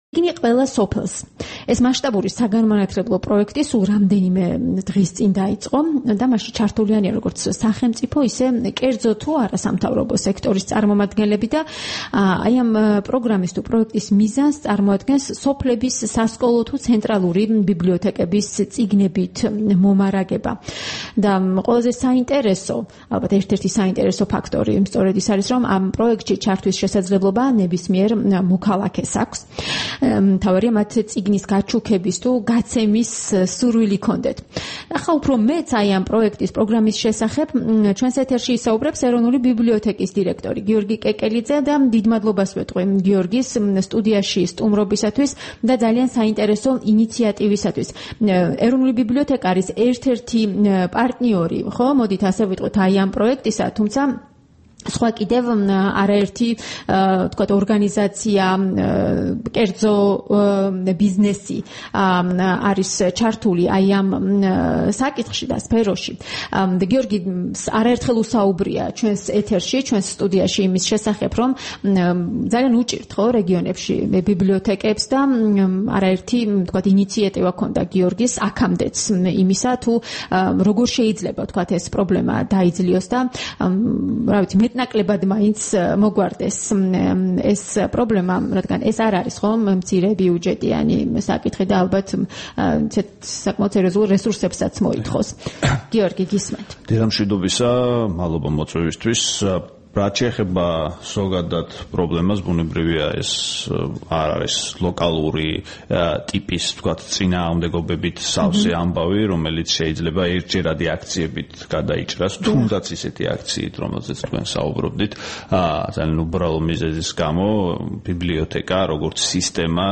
12 აგვისტოს რადიო თავისუფლების დილის გადაცემის სტუმარი იყო ეროვნული ბიბლიოთეკის დირექტორი გიორგი კეკელიძე.
საუბარი გიორგი კეკელიძესთან